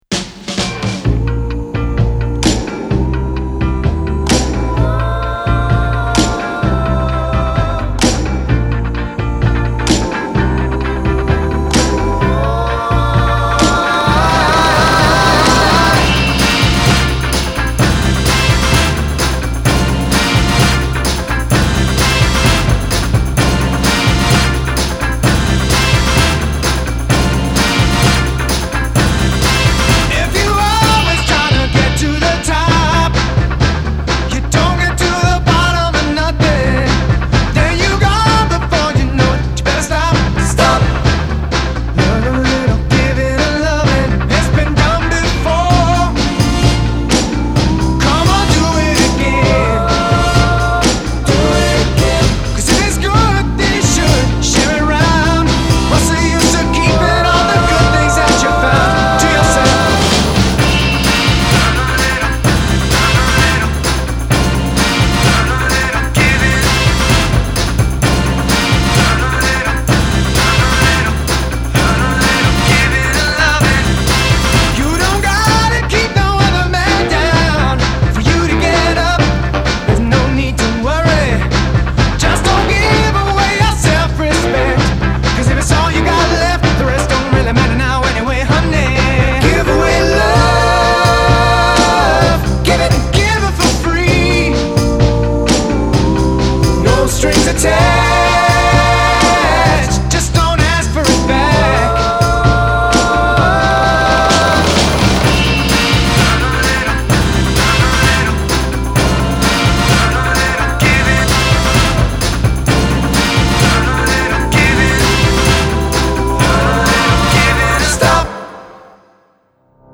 The light jazz guitar, those great harmonies.